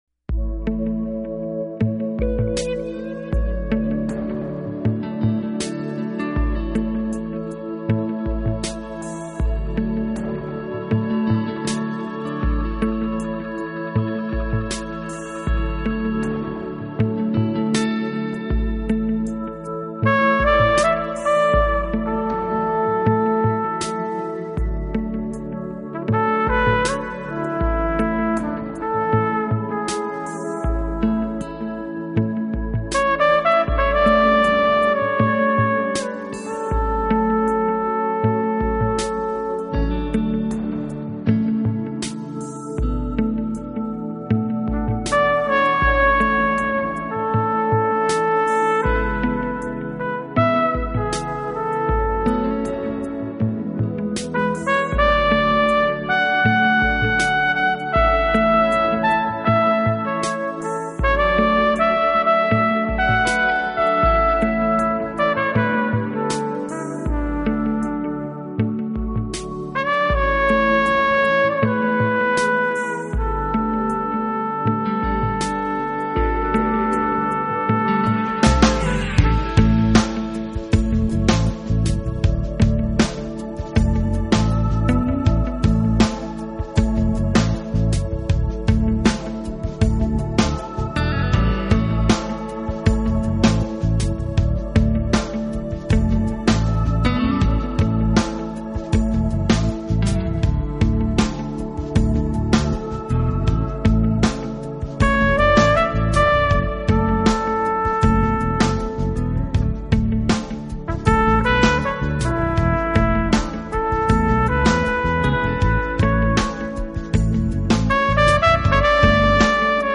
【爵士小号】
音乐风格：Jazz
这张无以伦比的专辑让听众们感受到了融合爵士（Jazz Fusion)的希望。
他吹奏得暧昧不明，带着暖色调的曲子分外引人遐思。